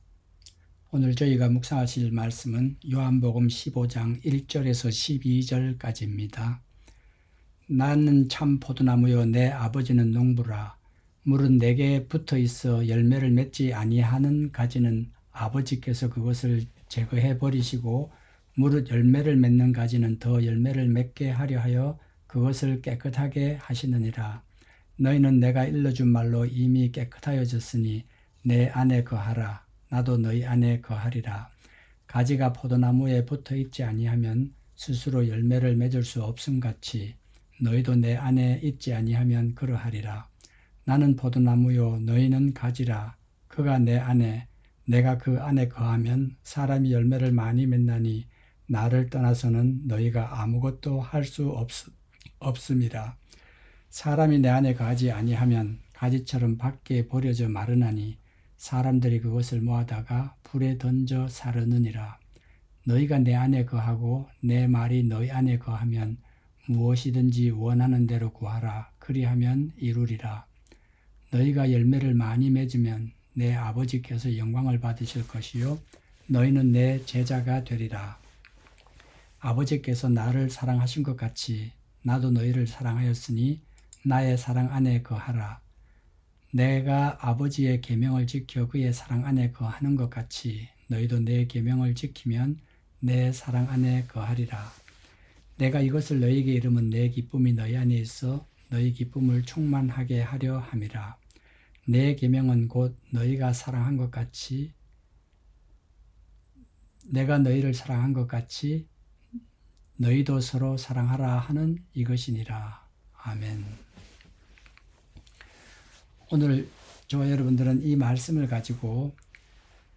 새벽설교